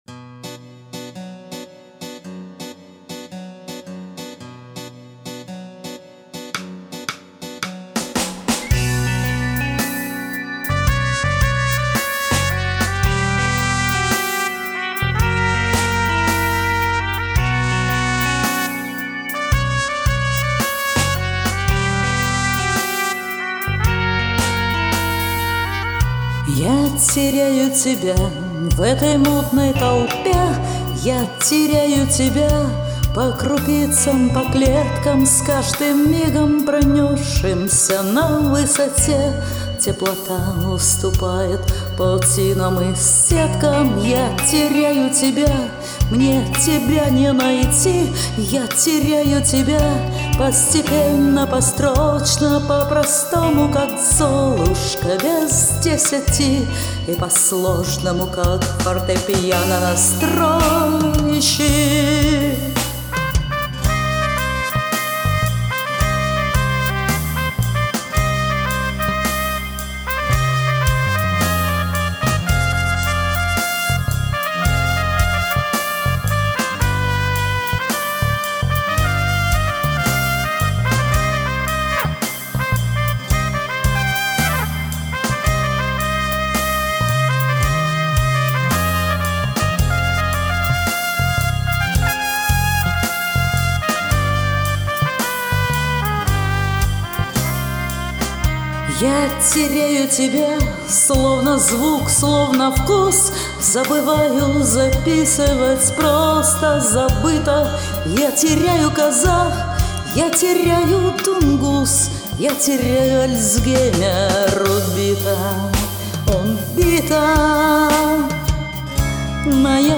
Женский рок - особая категория...........
( явно же замедлен темп) Но увы, другой не нашлось!